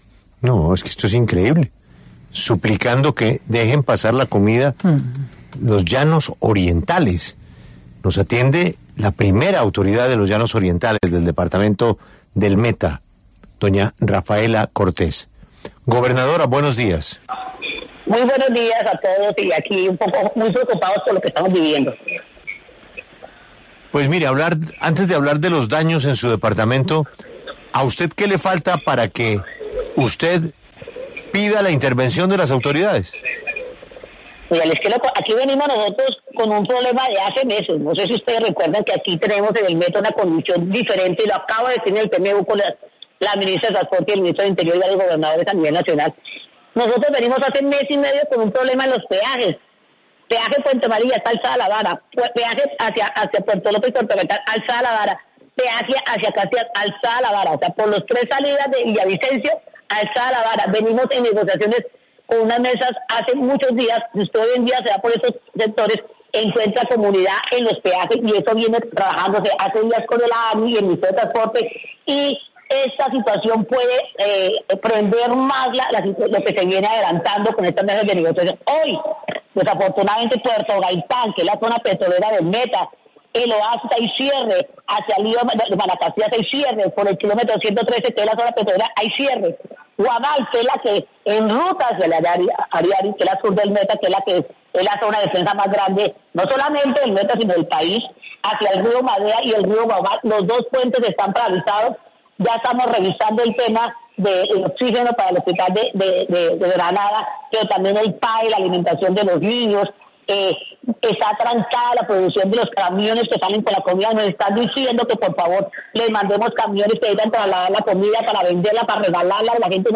Rafaela Cortés, gobernadora del Meta, habló en La W sobre las afectaciones que ha sufrido el departamento por cuenta del paro de transportadores que se adelanta desde hace tres días.